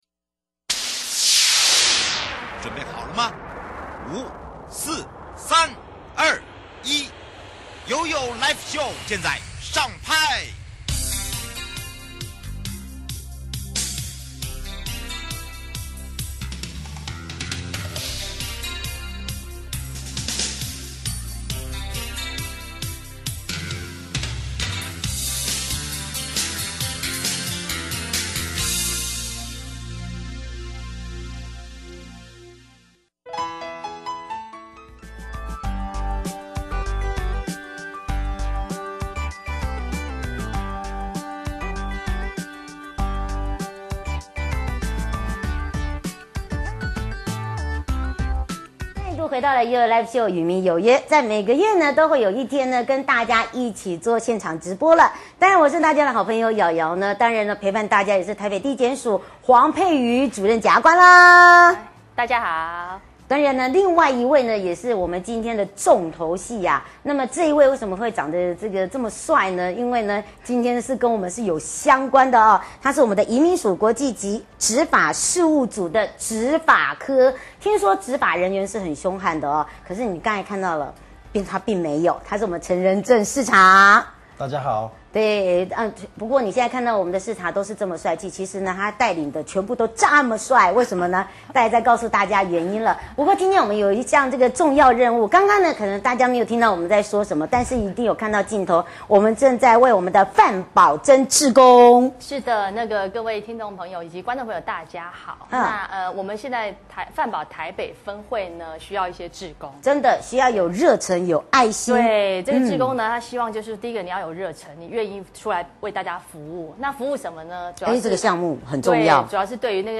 (直播) 節目內容： 外配入境我國之面談審查及假結婚真賣淫相關法律案例實錄：什麼是婚姻法？